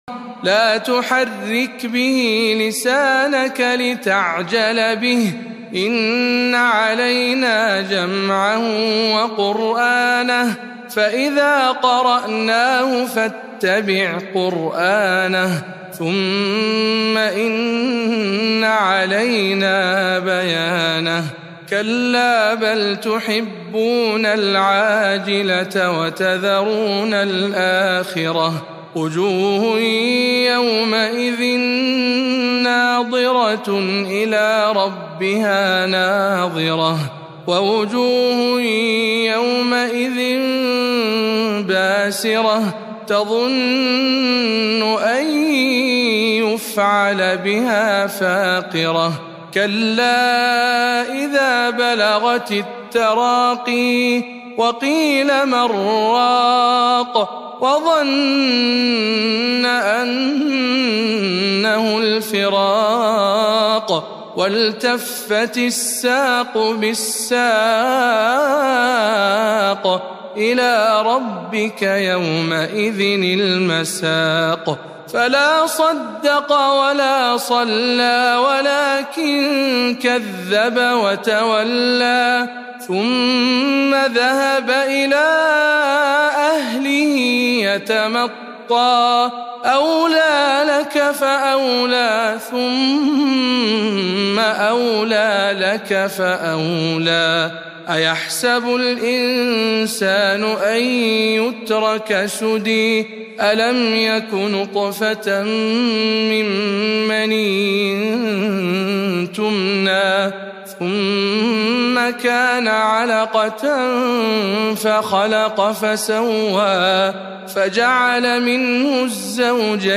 تلاوة مميزة من سورة القيامة